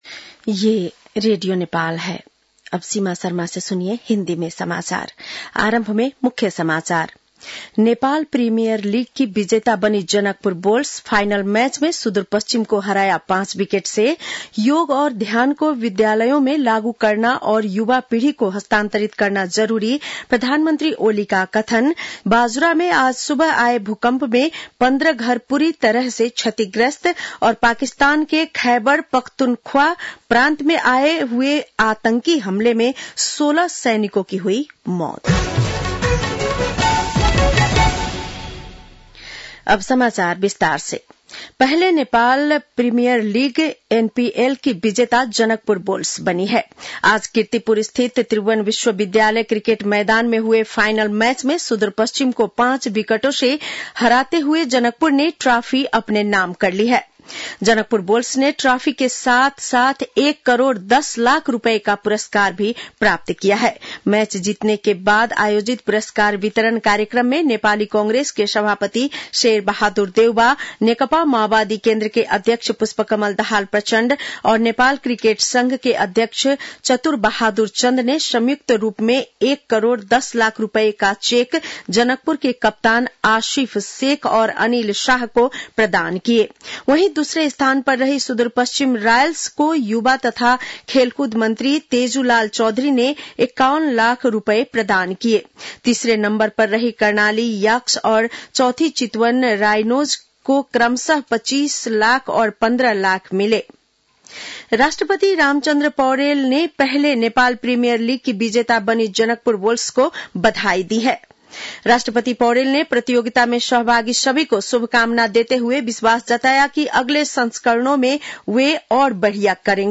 बेलुकी १० बजेको हिन्दी समाचार : ७ पुष , २०८१
10-PM-Hindi-News-9-6.mp3